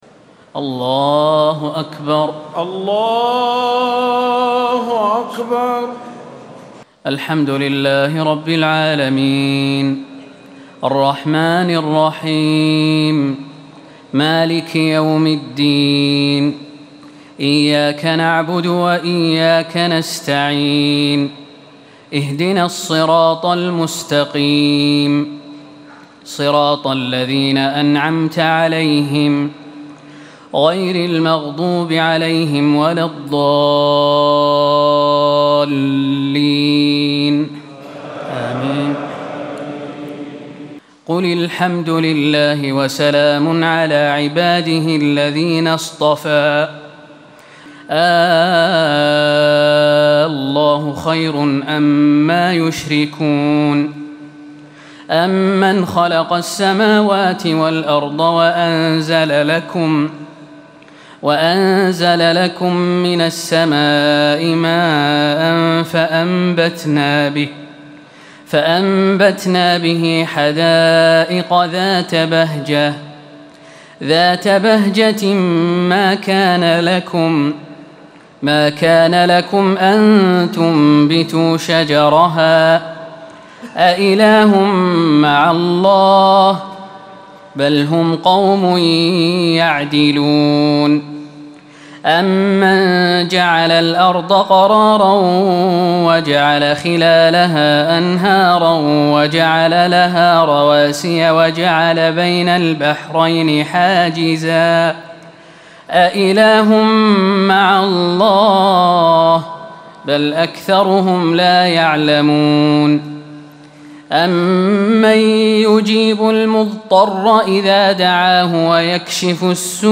تراويح الليلة التاسعة عشر رمضان 1437هـ من سورتي النمل(59-93) و القصص(1-50) Taraweeh 19 st night Ramadan 1437H from Surah An-Naml and Al-Qasas > تراويح الحرم النبوي عام 1437 🕌 > التراويح - تلاوات الحرمين